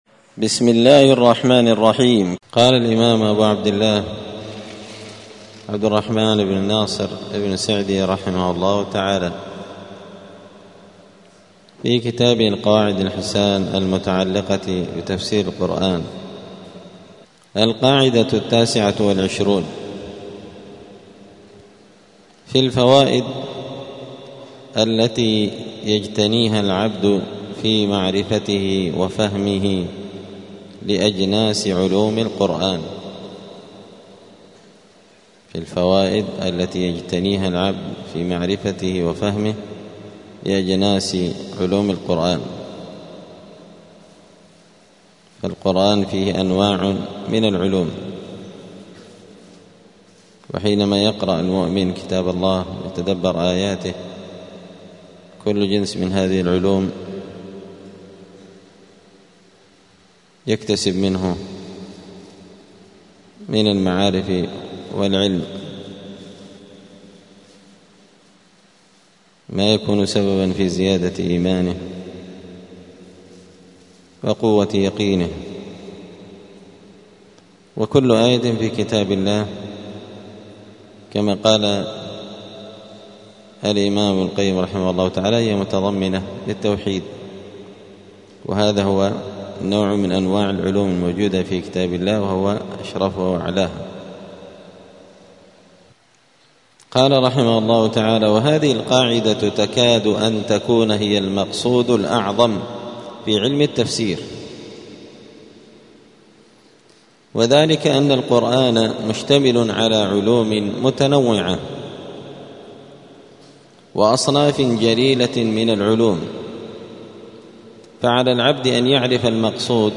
دار الحديث السلفية بمسجد الفرقان قشن المهرة اليمن
40الدرس-الأربعون-من-كتاب-القواعد-الحسان.mp3